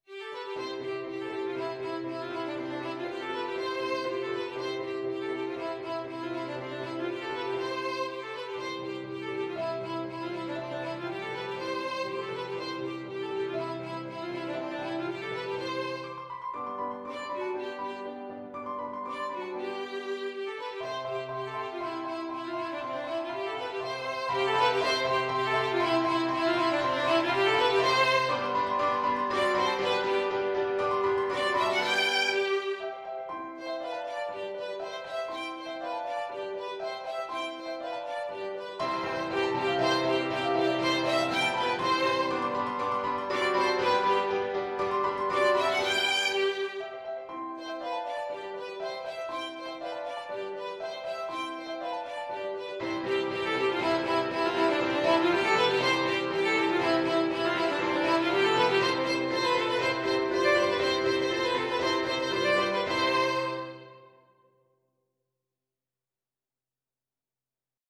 Violin
2/4 (View more 2/4 Music)
C major (Sounding Pitch) (View more C major Music for Violin )
Classical (View more Classical Violin Music)